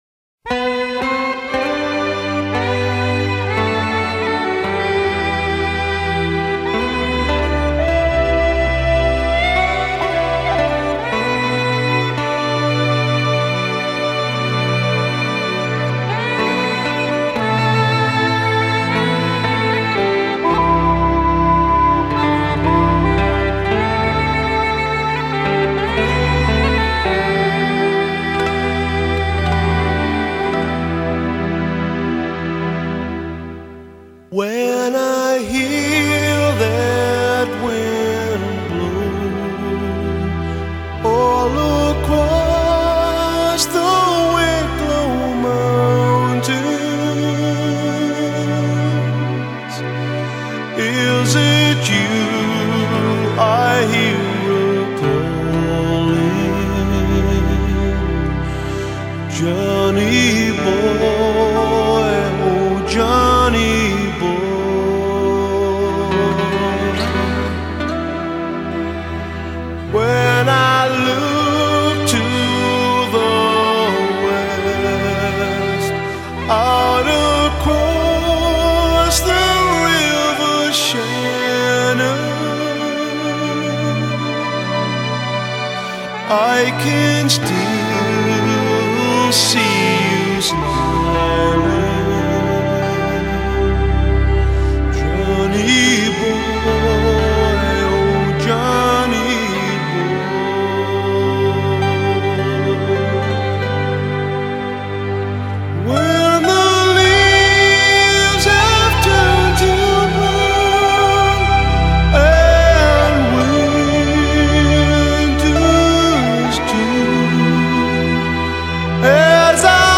一支有点悲伤的爱尔兰民谣 跟之前的欢快民谣完全不是一个感觉 爱尔兰人丰富感情的另一面 稍有了解的朋友应该都听过